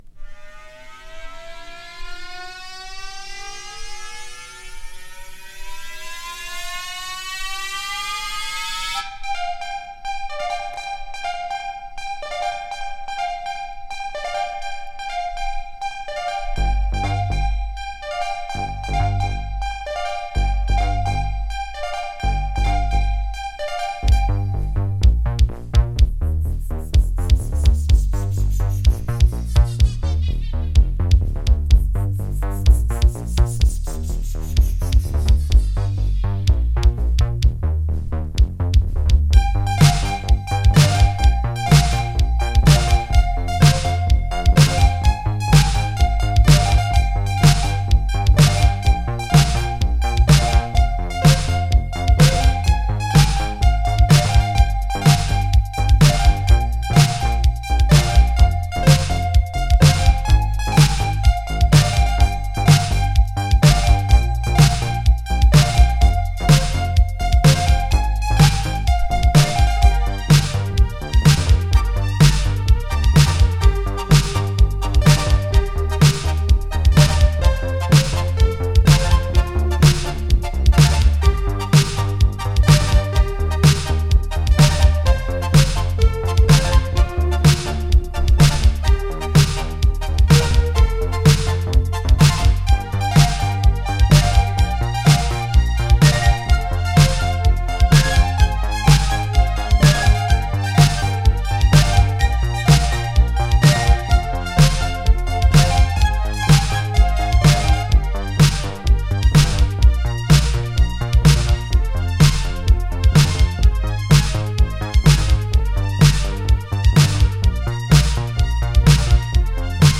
Obuscure Electro Disco from L.A.!
音程がハズレ気味のヴォーカルがなんとも言えない、B級感満載のオブスキュアなエレクトロ・ディスコサウンド！
HI-NRG